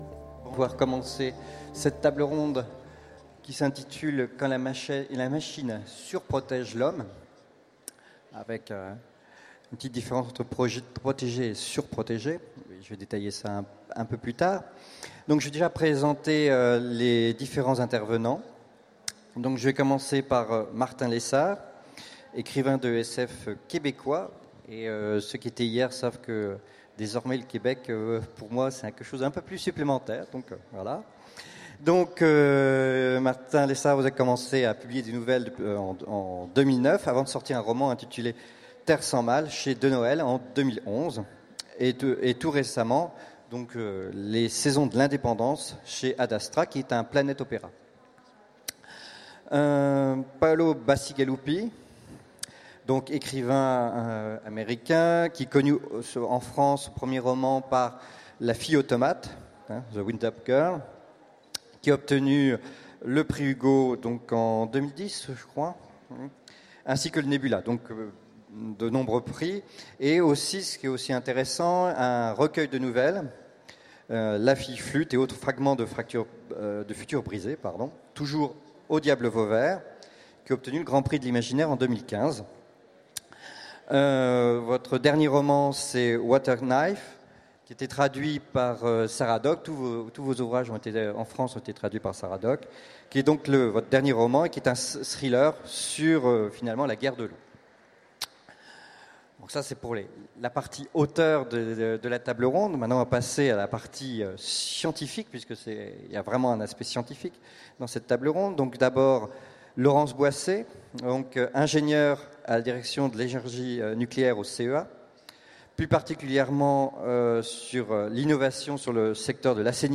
Utopiales 2016 : Conférence Quand la machine (sur) protège l’homme